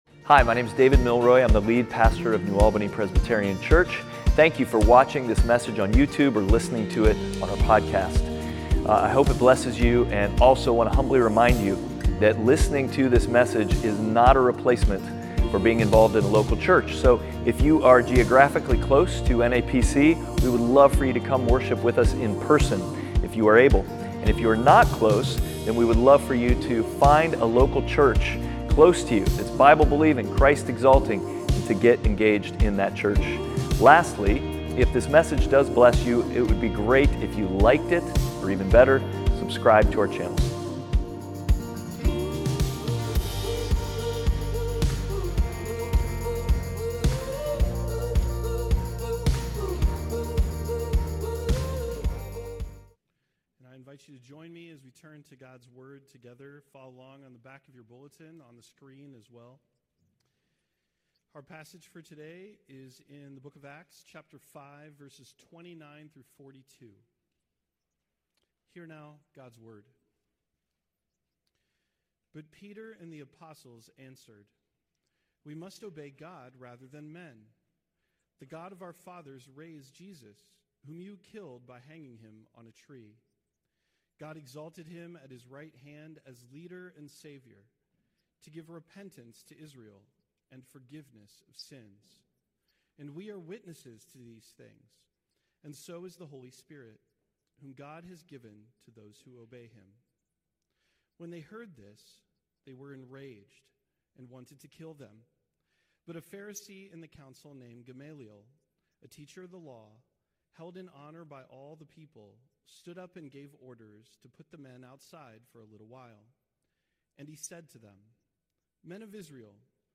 Outward Passage: Acts 5:29-42 Service Type: Sunday Worship « Outward